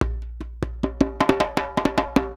Index of /90_sSampleCDs/USB Soundscan vol.36 - Percussion Loops [AKAI] 1CD/Partition A/19-100JEMBE
100 JEMBE8.wav